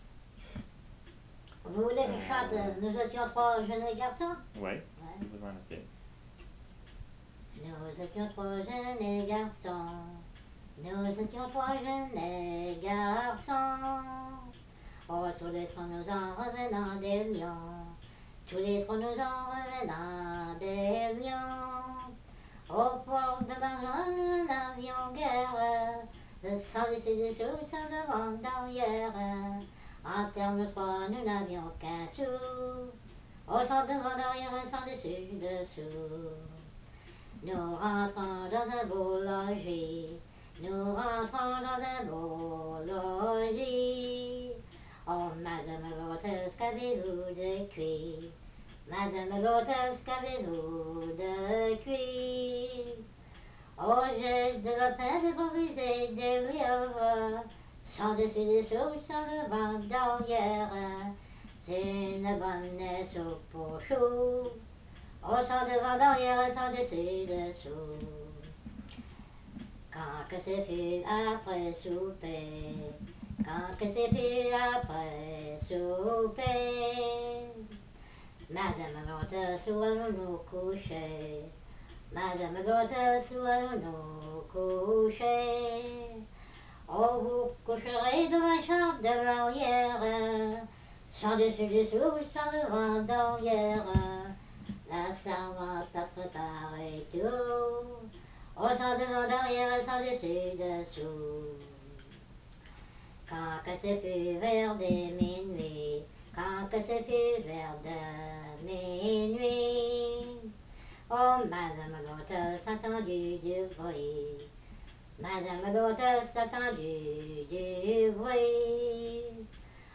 Chanson Item Type Metadata
Emplacement La Grand'Terre